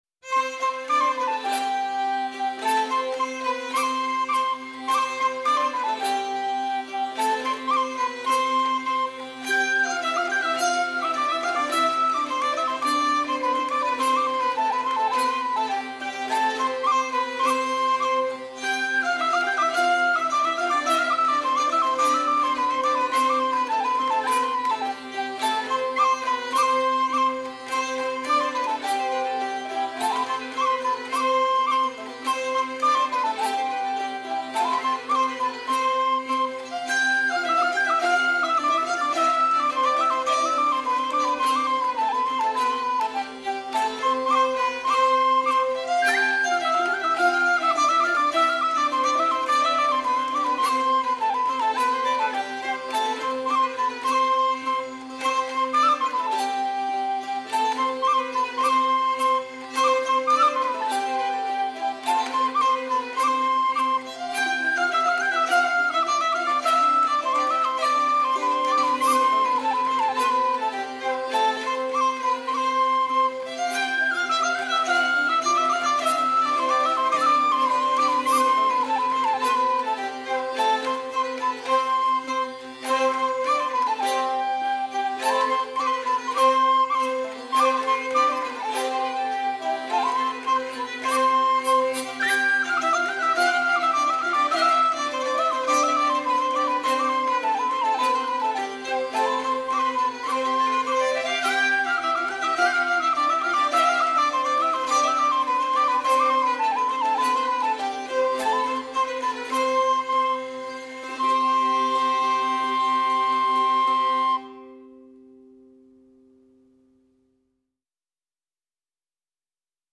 Musik nach einer französischen branle von 1589, Chorsatz von Charles Wood (1866-1926) Text von George Ratcliffe Woodward (1848–1934)